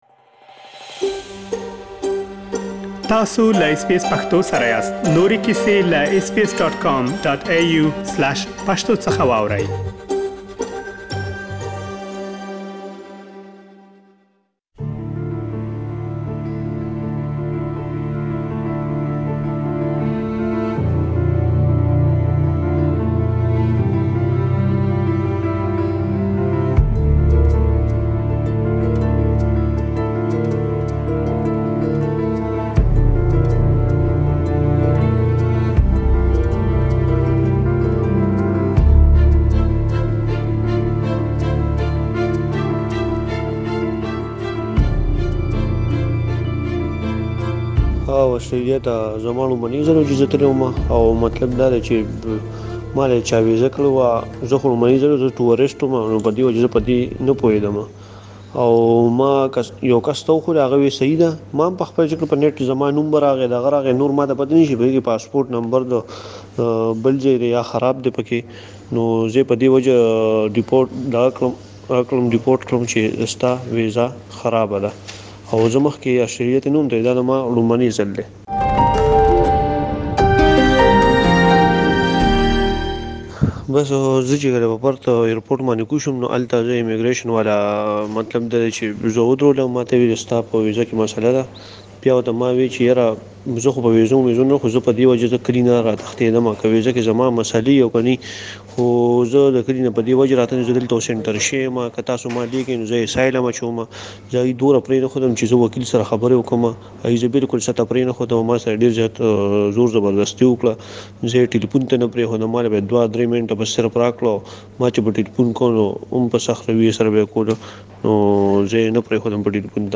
له واټس اپ له لارې يې خپله کيسه له اس بي اس پښتو خپرونې سره شريکه کړه.